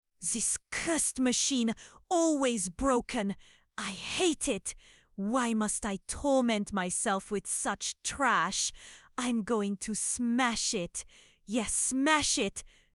Rename Cursed Machine![sage English french accent intense disgust revulsion abhorrence and loathing]This ......it!!!.mp3 to 4.mp3